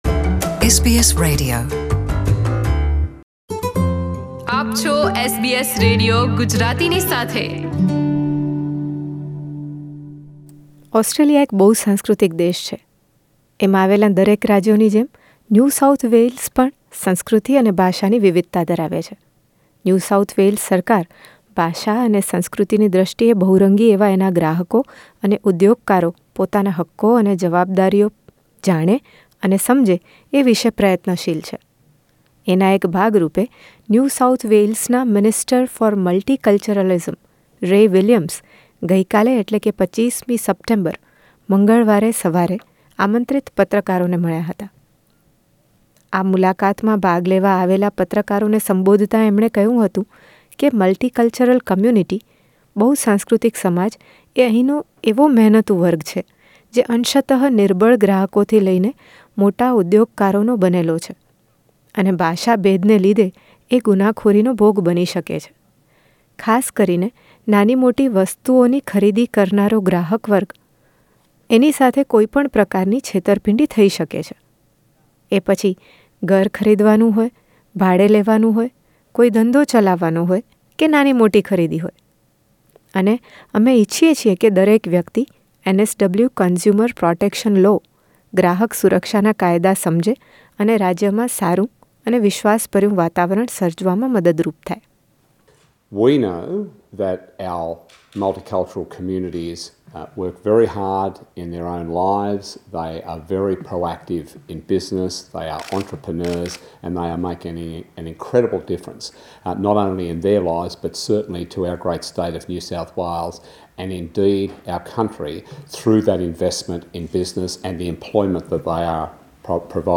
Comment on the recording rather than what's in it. Multicultural media briefing at NSW Parliament house on 25th Sept 2018.